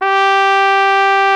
Index of /90_sSampleCDs/Roland L-CD702/VOL-2/BRS_Flugelhorn/BRS_Flugelhorn 1